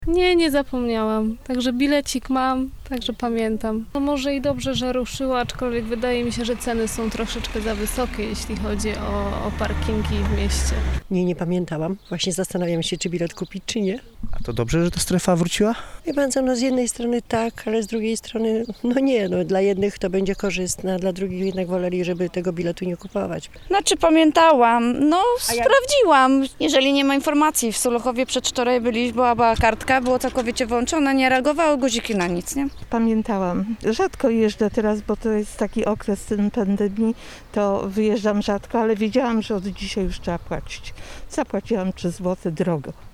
Dziś odwiedziliśmy kilka zielonogórskich parkingów i sprawdziliśmy czy mieszkańcy pamiętali o opłatach za parkowanie: